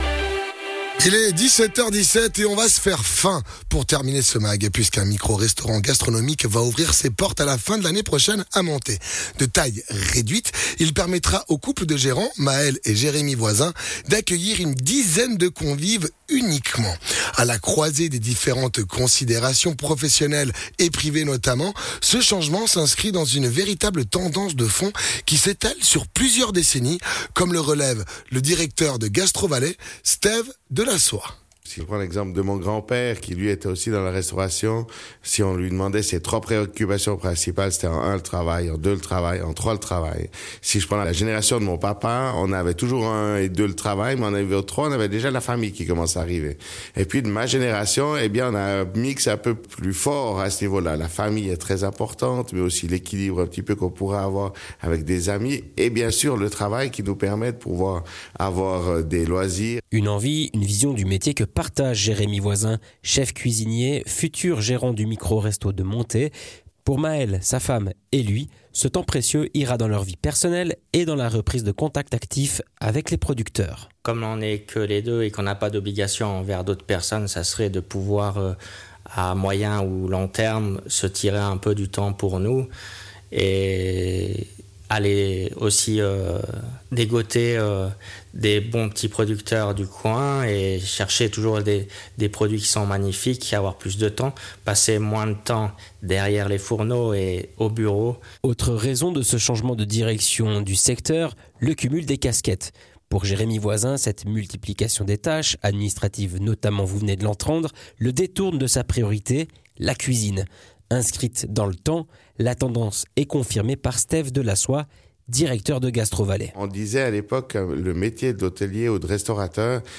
chef-cuisinier